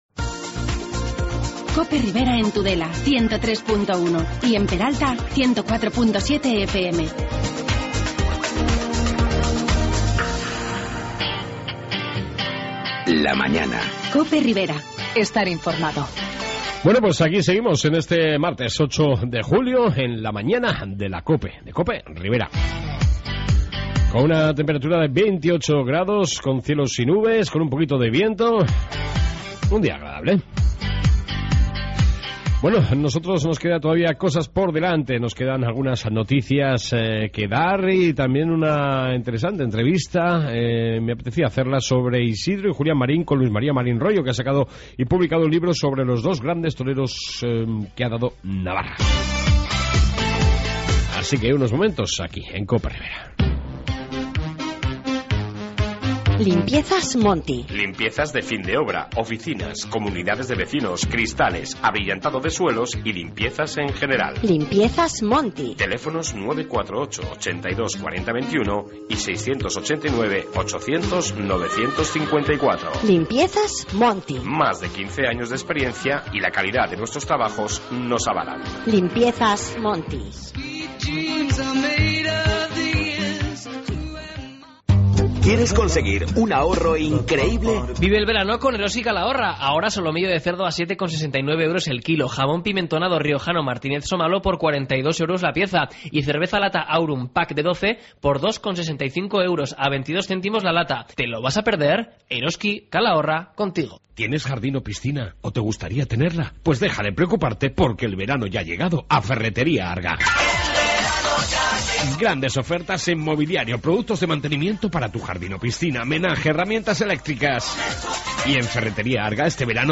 AUDIO: En esta 2 parte interesante entrevista sobre los 2 mejores toreros Navarros de la Historia Julian e Isidro Marín